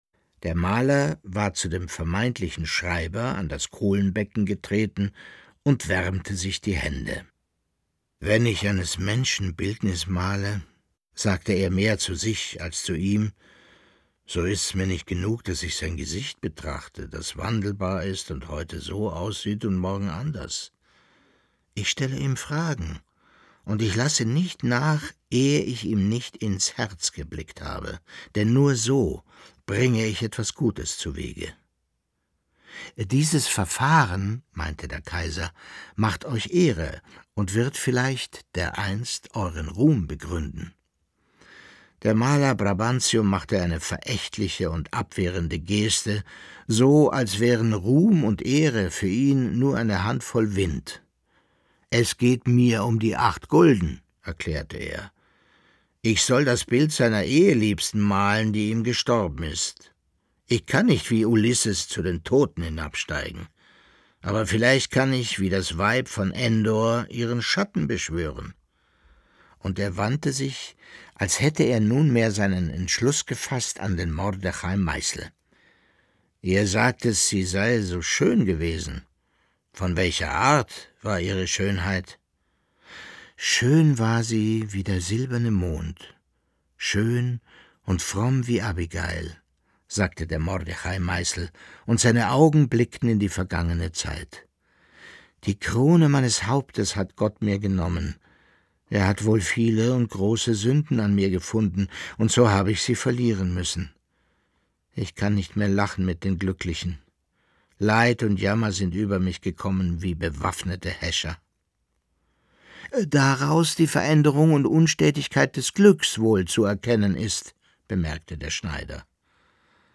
Leo Perutz: Nachts unter der steinernen Brücke (15/25) ~ Lesungen Podcast